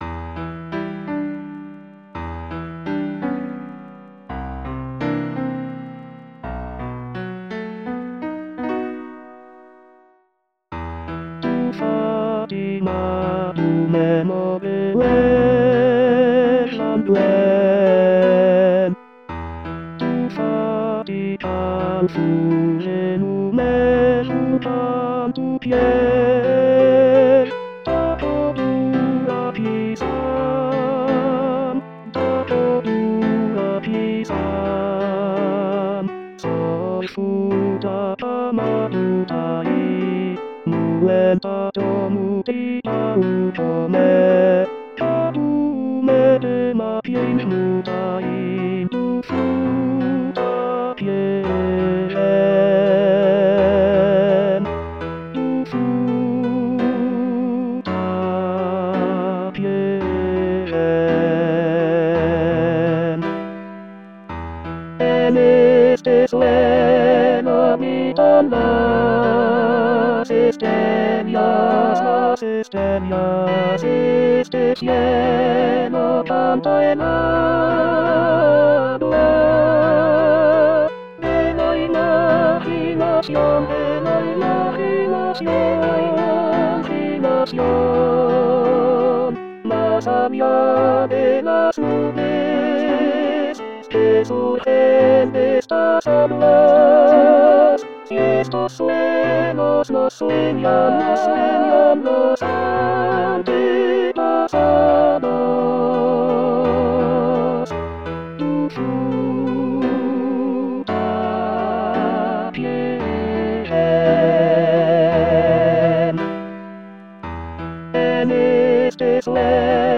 Tenor Tenor 2